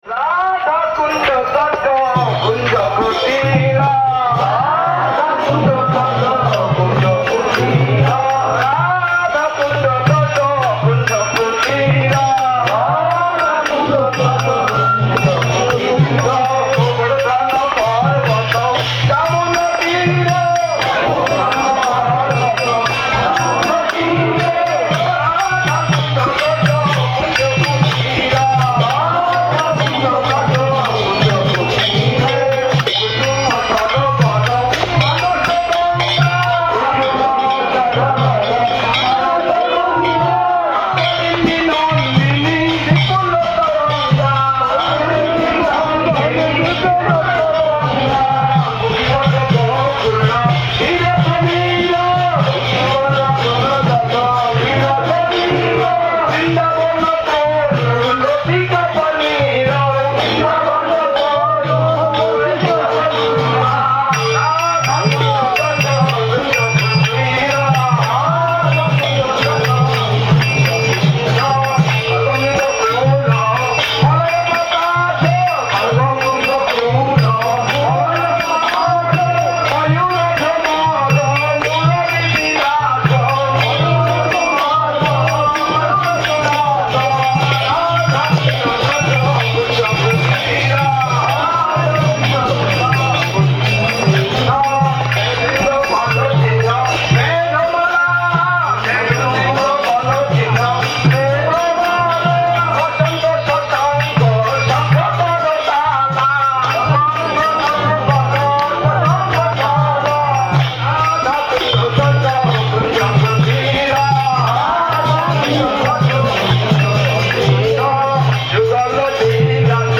Gaura Purnima Parikrama 2012
Kirttan